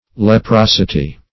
Search Result for " leprosity" : The Collaborative International Dictionary of English v.0.48: Leprosity \Le*pros"i*ty\ (l[-e]*pr[o^]s"[i^]*t[y^]), n. The state or quality of being leprous or scaly; also, a scale.